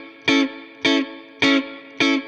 DD_StratChop_105-Amaj.wav